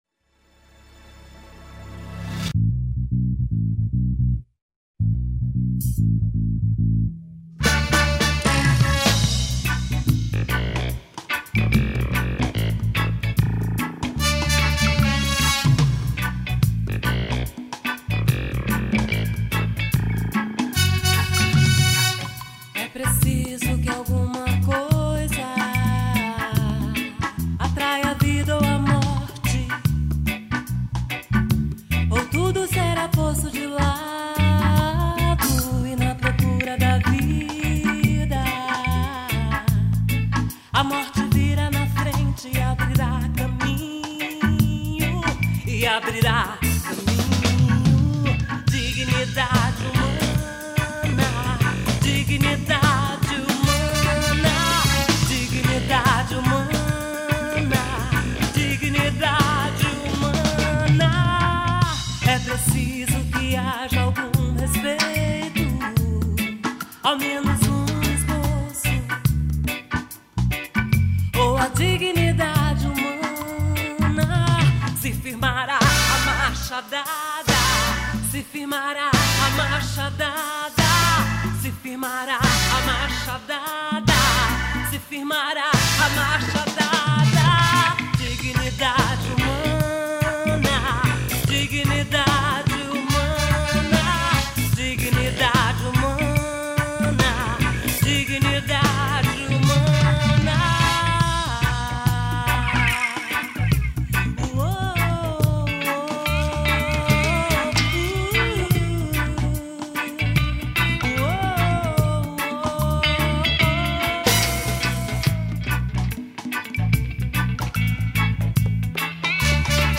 1710   04:15:00   Faixa:     Reggae